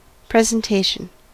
ÄäntäminenUS:
• IPA: [ˌpri.ˌzɛn.ˈteɪ.ʃən]